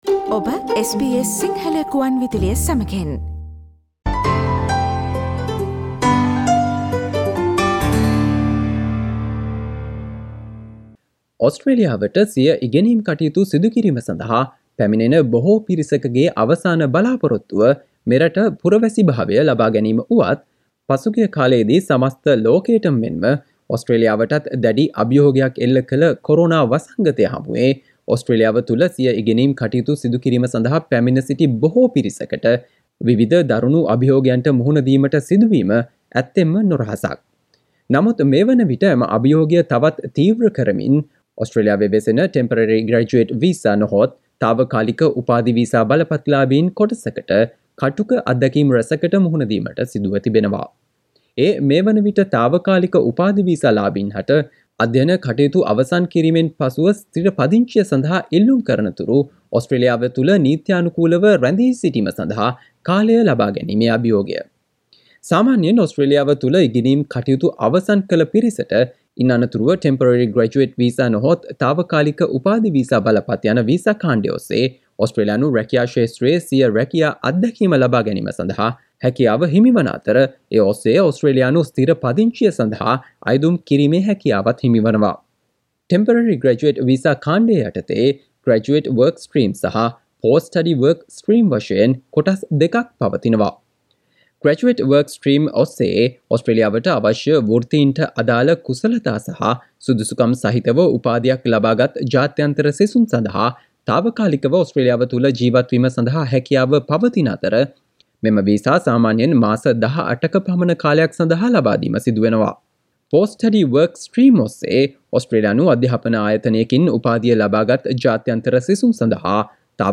ඕස්ට්‍රේලියාවේ සිටින ශ්‍රී ලාංකික 'තාවකාලික උපාධි වීසා' ලාභීන්, තමන් මුහුණ දී සිටින දුෂ්කරතාවය ගැන SBS සිංහල ගුවන් විදුලියට විස්තර කළ අයුරු.